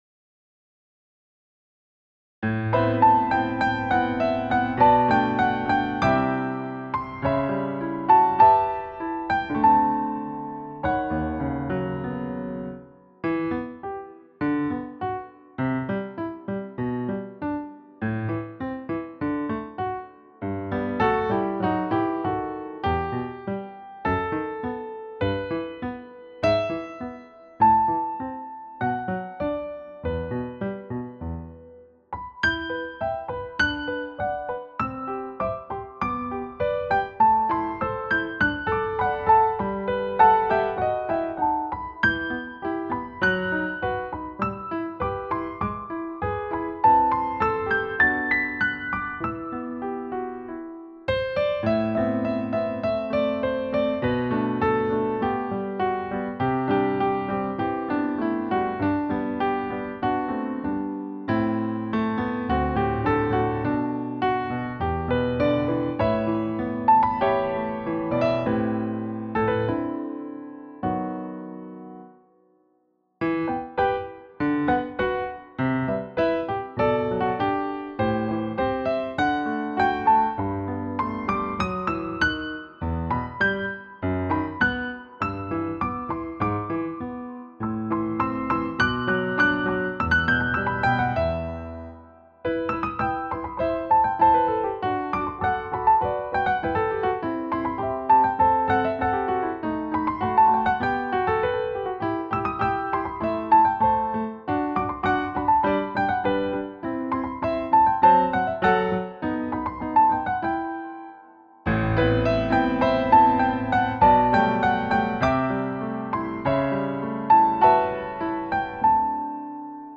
Easy Sheet Music
• piano accompaniment
Key Signature: E minor